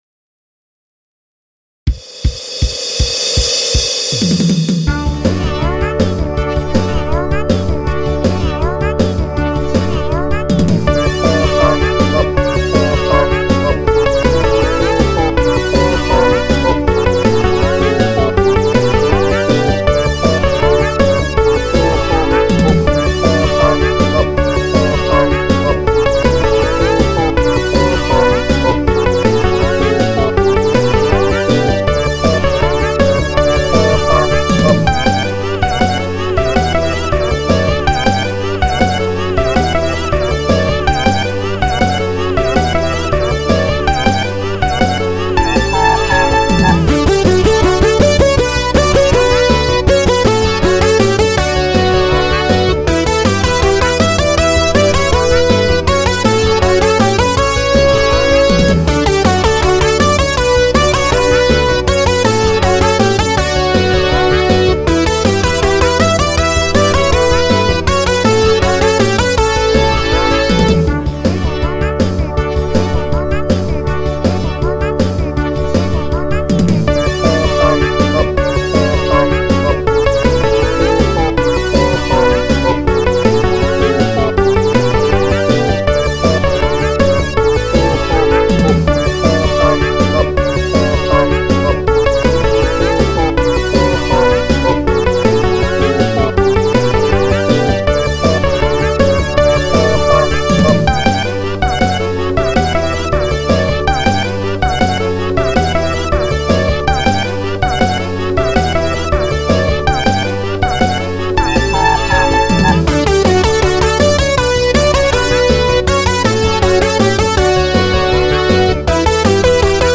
ドラムスはSC-88Pro。それ以外は諸々のソフトシンセ(CronoX3,Synth1,Alpha,MEIKO)を使用。